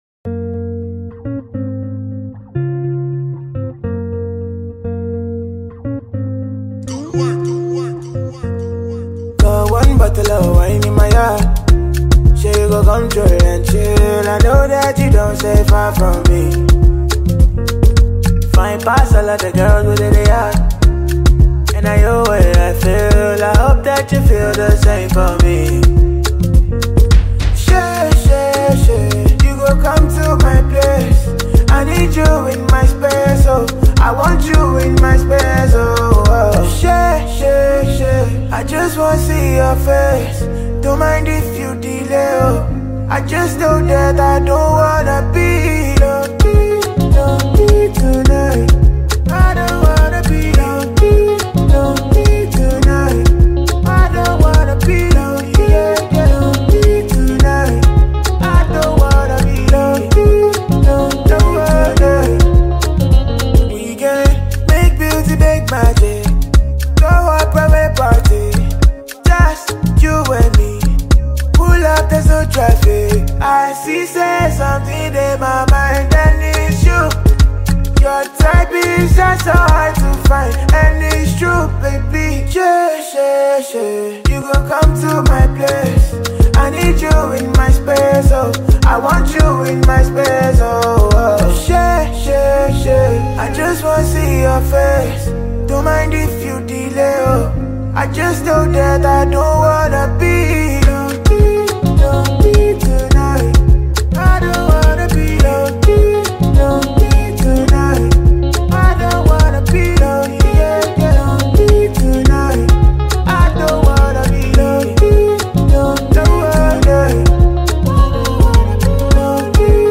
this infectious sound